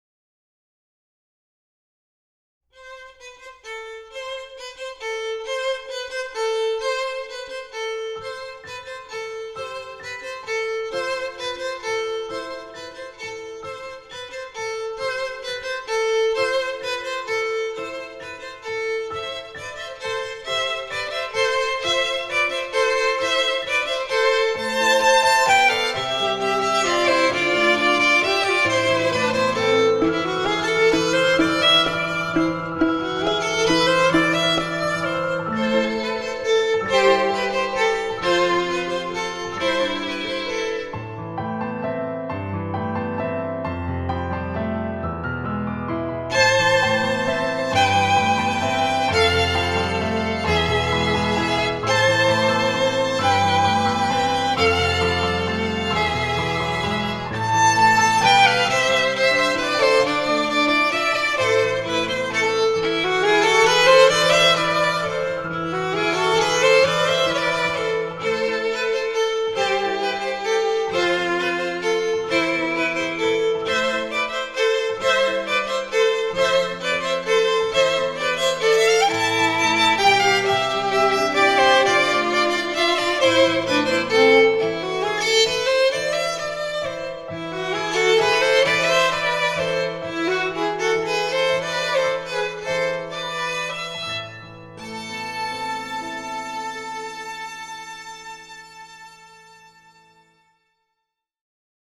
Instrumentation: 2 Violins with Piano accompaniment